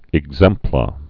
(ĭg-zĕmplə)